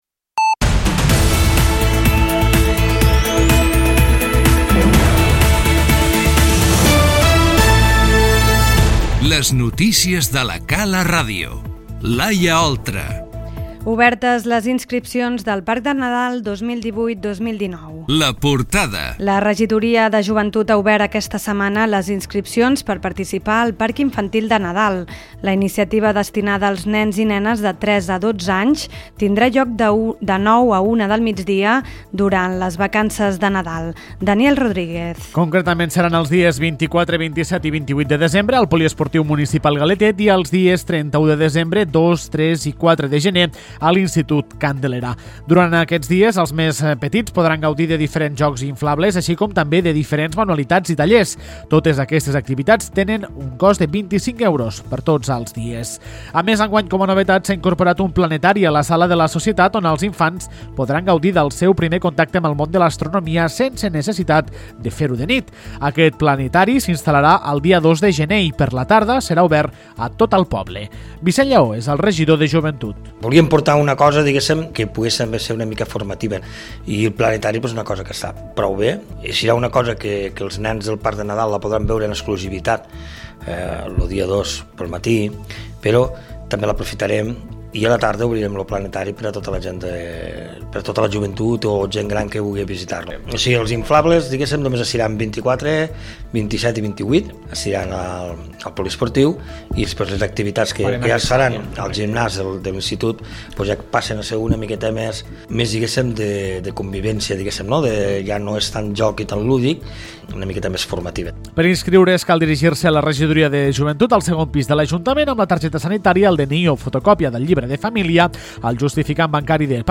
Les notícies 11/12/2018